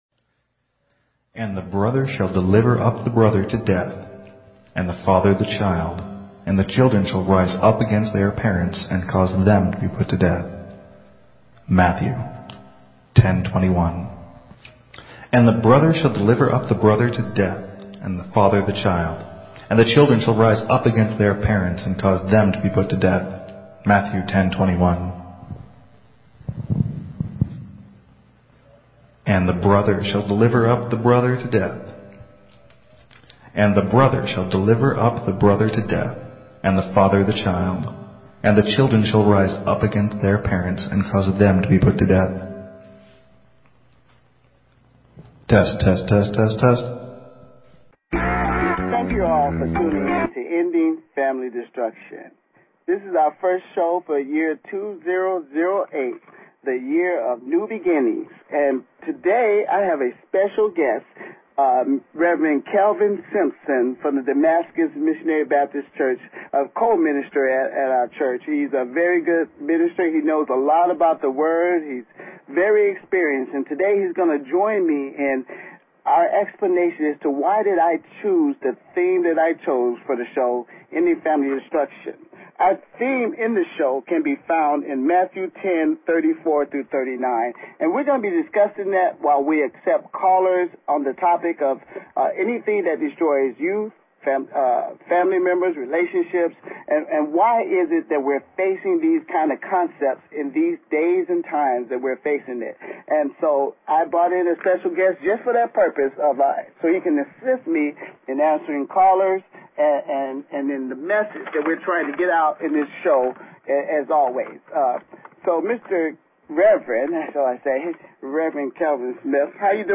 Talk Show Episode, Audio Podcast, Ending_Family_Destruction and Courtesy of BBS Radio on , show guests , about , categorized as